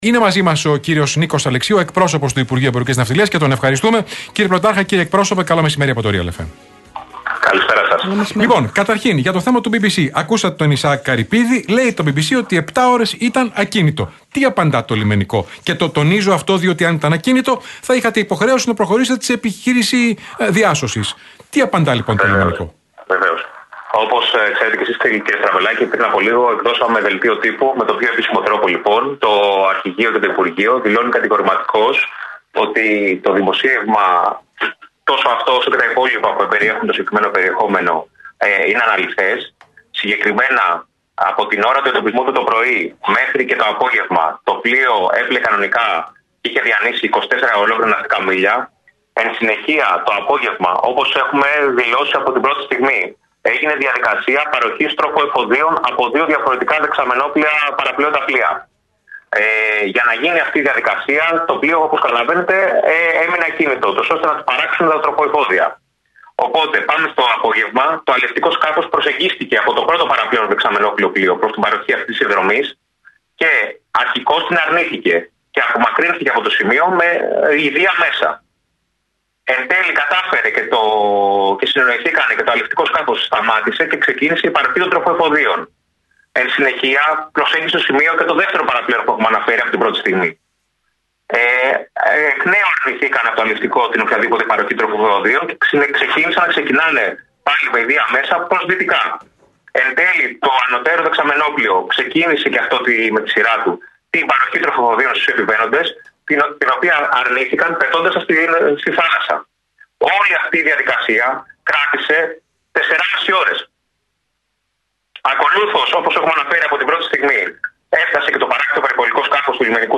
στο μαγκαζίνο του Realfm 97,8 σχετικά με το δημοσίευμα-ανάλυση του ΒΒC για το ναυάγιο στα ανοιχτά της Πύλου.